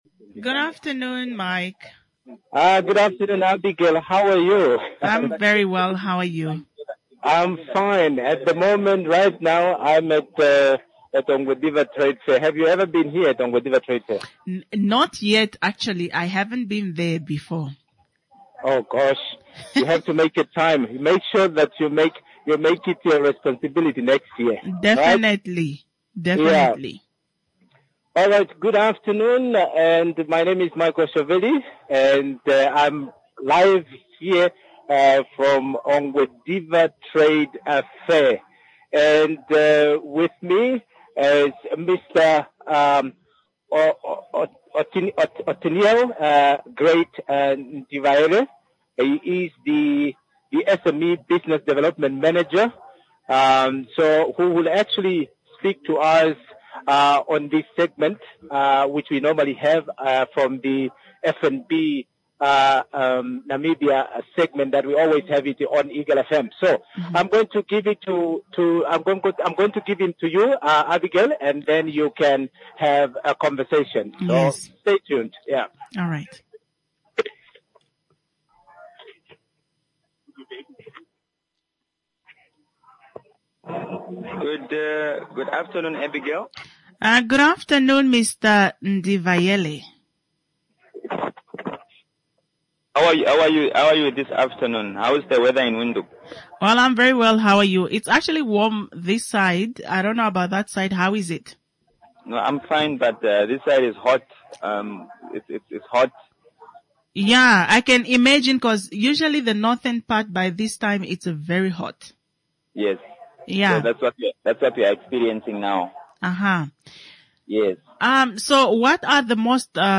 AN INTERVIEW WITH FNB ABOUT THEIR SERVICES STRAIGHT FROM ONGWEDIVA ANNUAL TRADEFAIR.